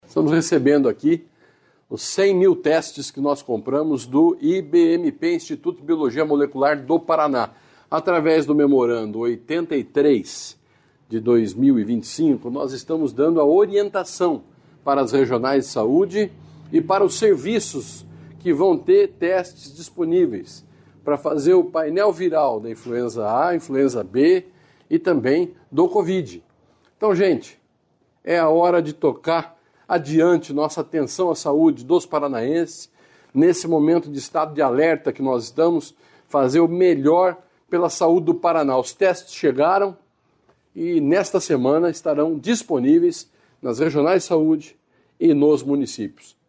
Sonora do secretário Estadual da Saúde, Beto Preto, sobre a aquisição e distribuição de 100 mil testes rápidos de detecção da gripe e da Covid-19 | Governo do Estado do Paraná